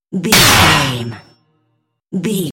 Dramatic hit deep metal clicnk
Sound Effects
heavy
dark
aggressive